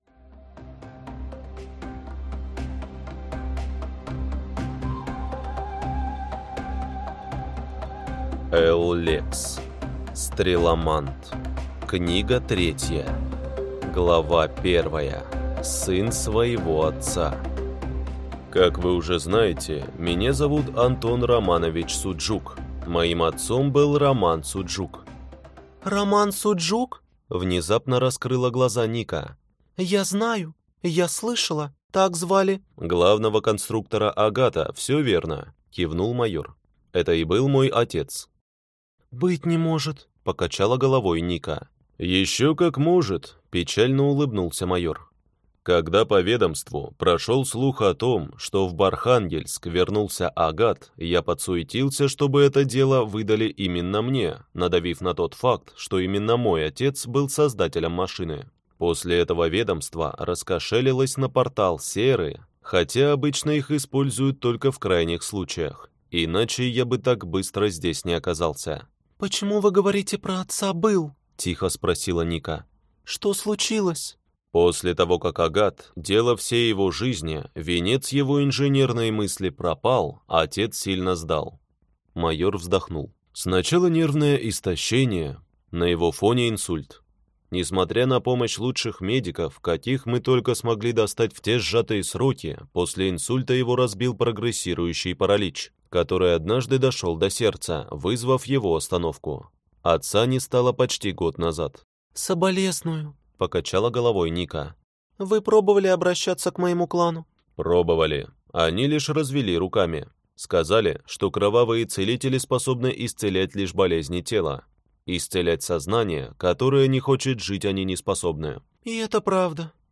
Аудиокнига Стреломант. Книга 3 | Библиотека аудиокниг